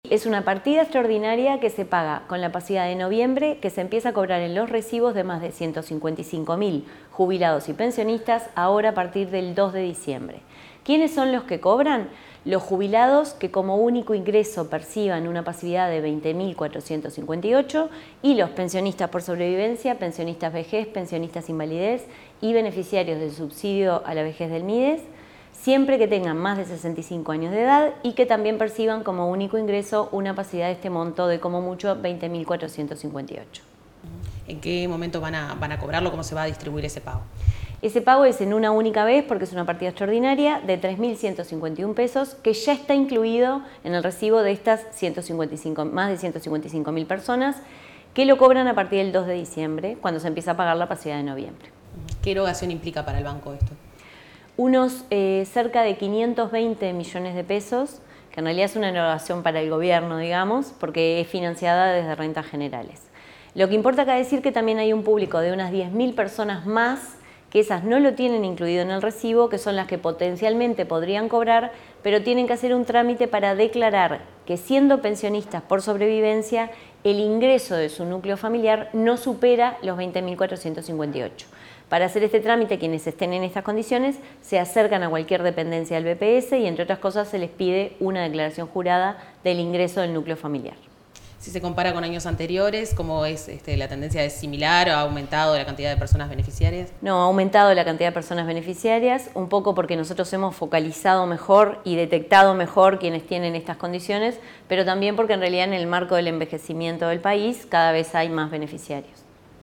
Declaraciones de la presidenta del BPS, Jimena Pardo